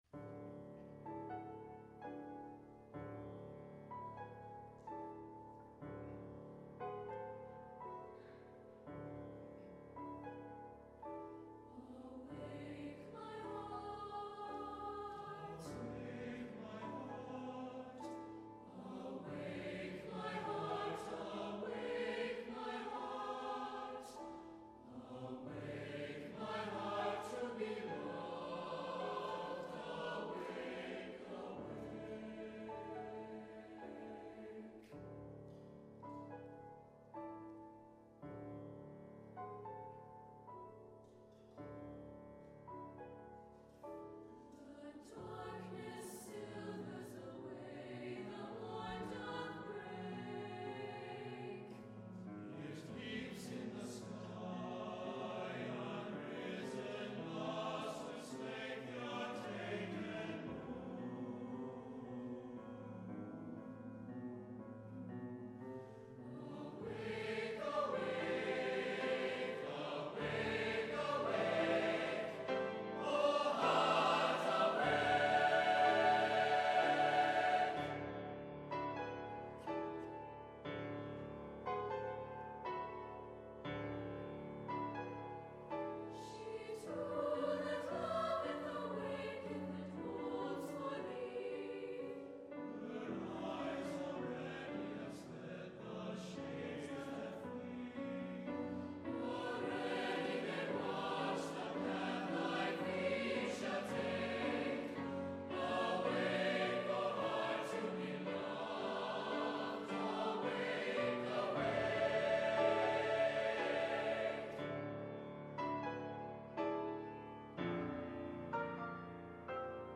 Voicing: SATB Divisi Accompaniment: With Piano
Music Category: Choral